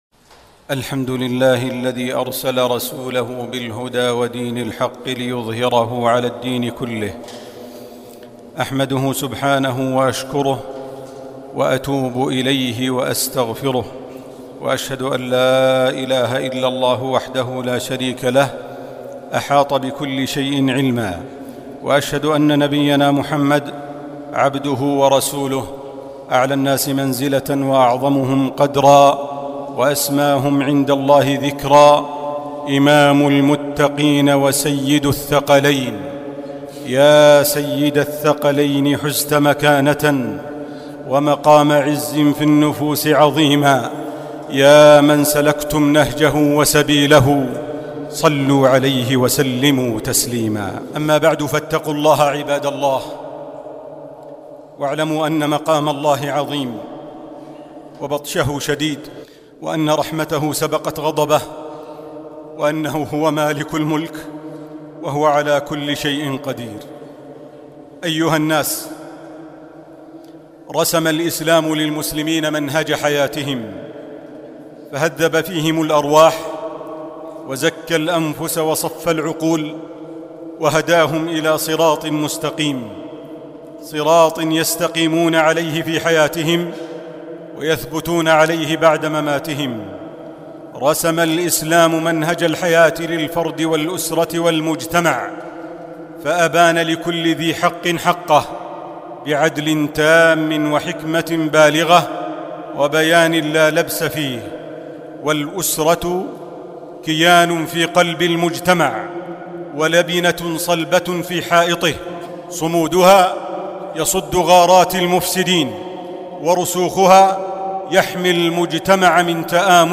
الخطب الصوتية